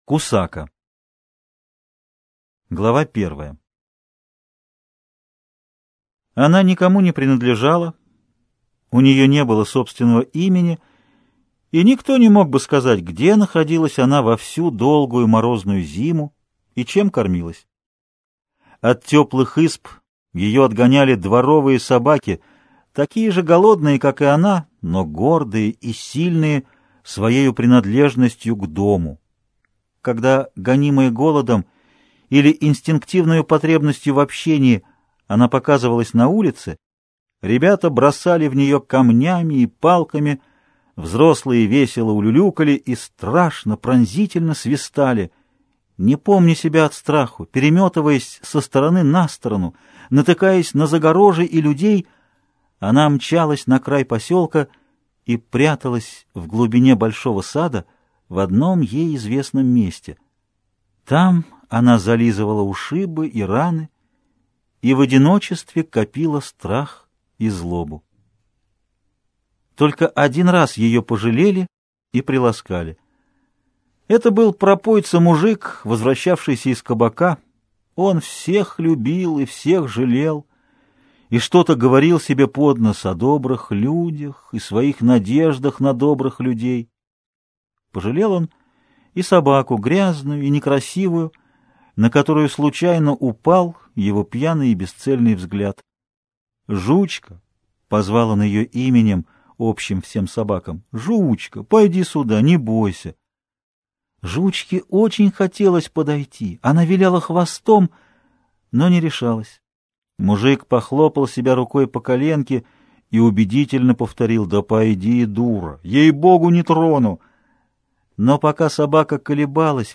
Аудиокнига Баргамот и Гараська | Библиотека аудиокниг